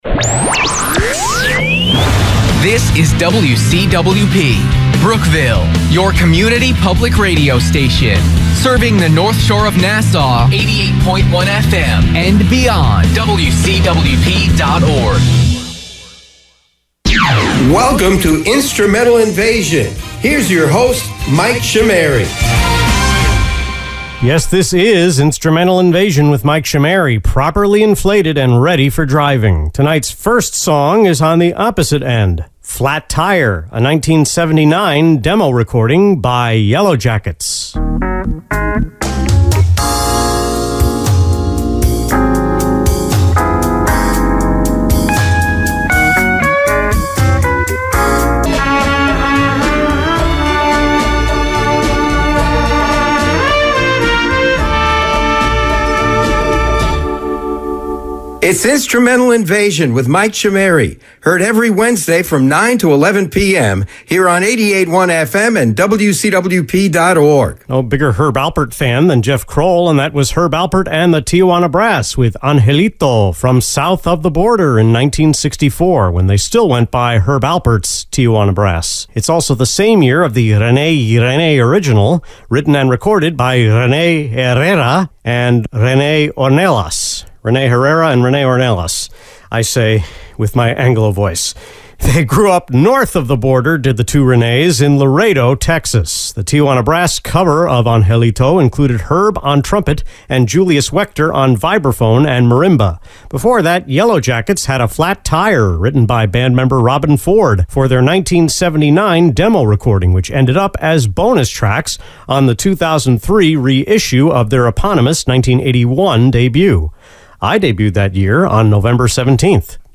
The August 31 Instrumental Invasion on WCWP was recorded over three days: two segments on July 2, three on the 3rd, and one on the 4th before intermittent daytime fireworks began.